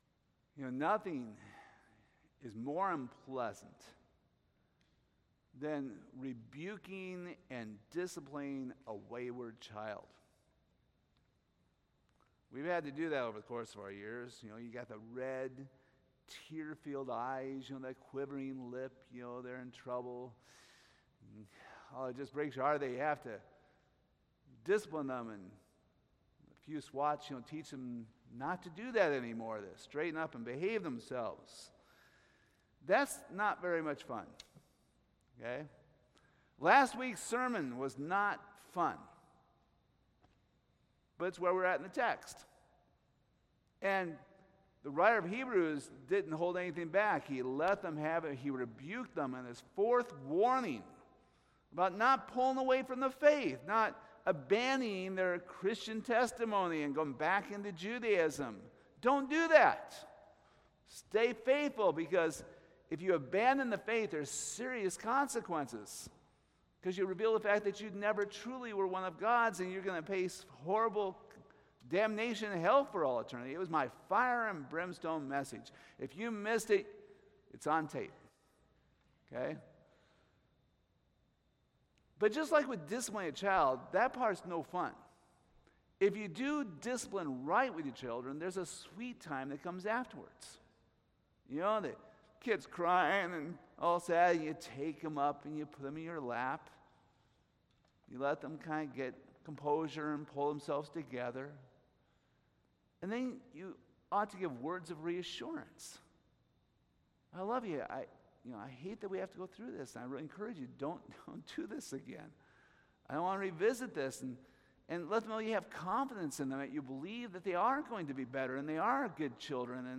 Hebrews 10:32-39 Service Type: Sunday Morning What is the Biblical response to persecution?